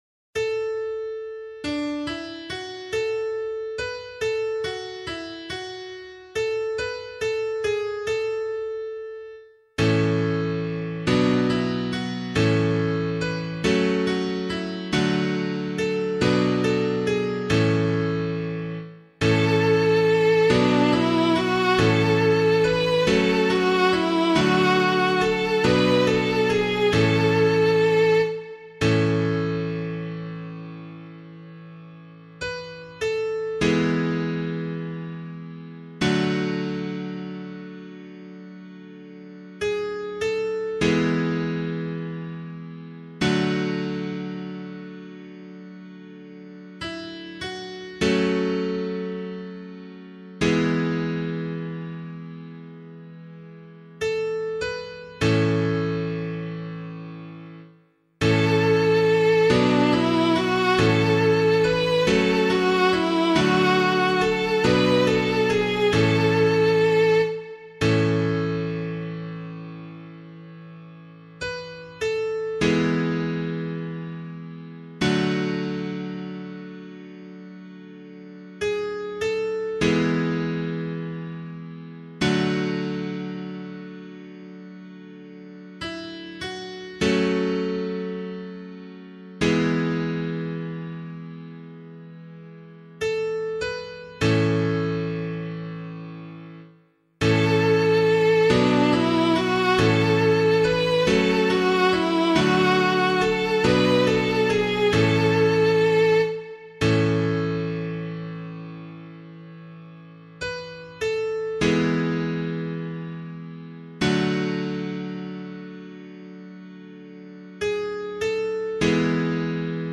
032 Pentecost Day Psalm [LiturgyShare 8 - Oz] - piano.mp3